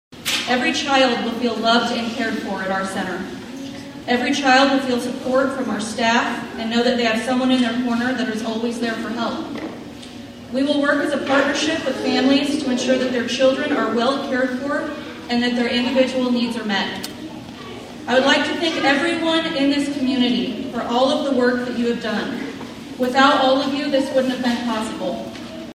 The communities support and involvement in this project was echoed by all who spoke at the ceremony.